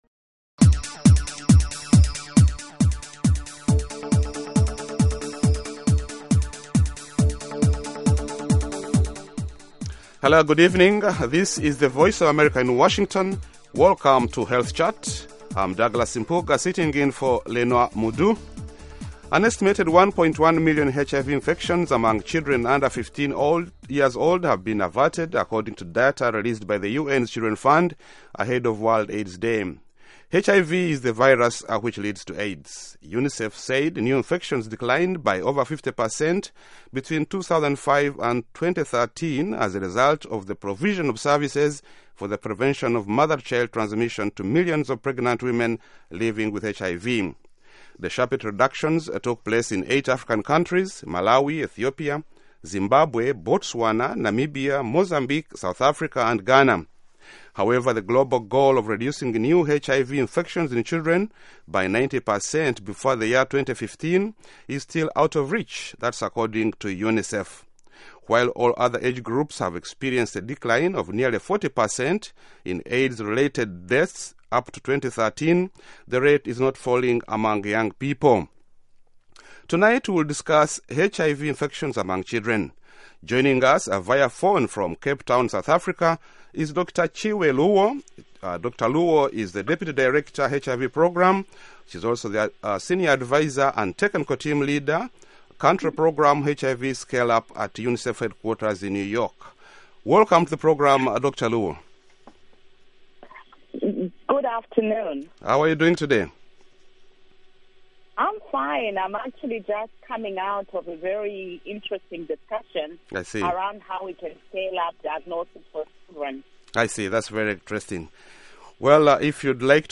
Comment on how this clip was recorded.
Health Chat is a live call-in program that addresses health issues of interest to Africa.